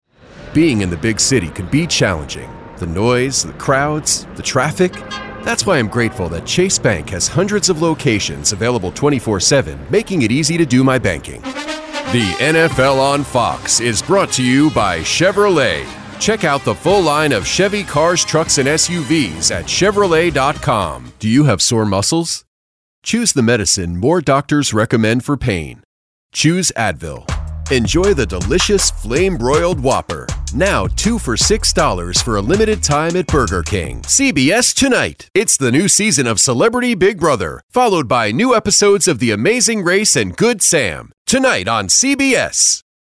I work out of my home studio.
US English voice over, trustworthy & real guy next door voice
Sprechprobe: Werbung (Muttersprache):